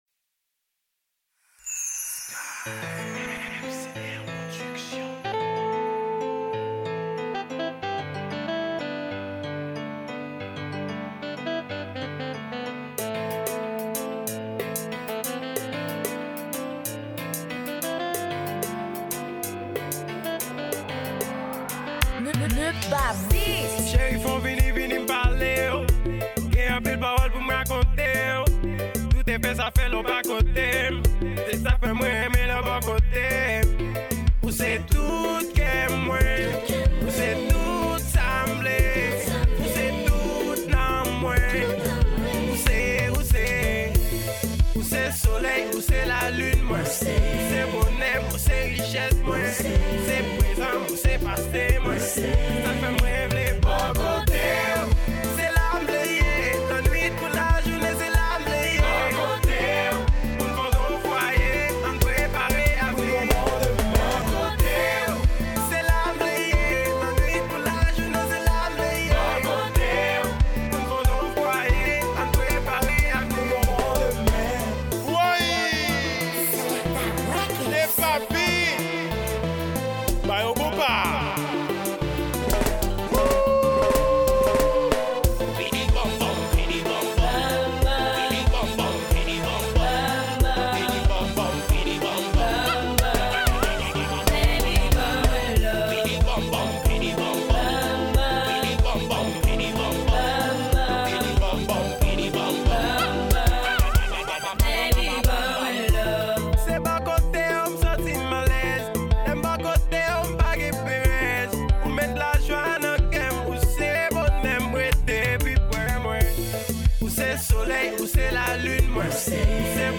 Genre: KONPA.